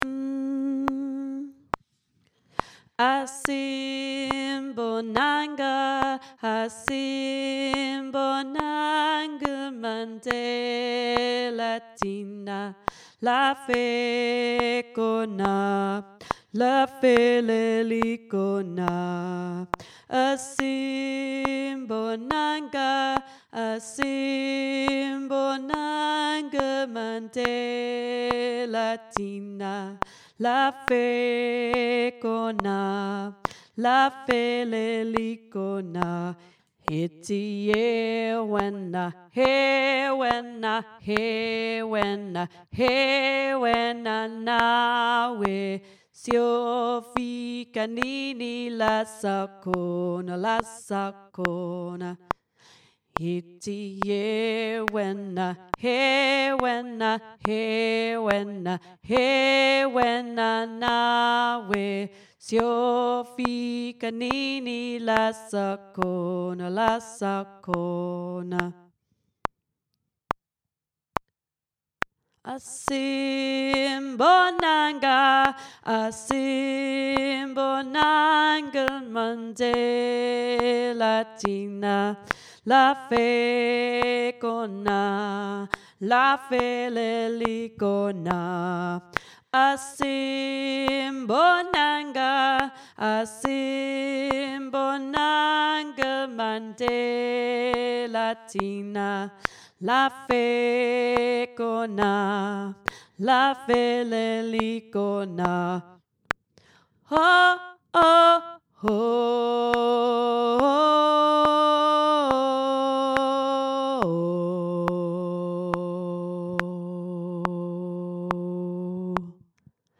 asimbonanga-tenor
asimbonanga-tenor.mp3